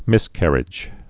(mĭskărĭj, mĭs-kăr-)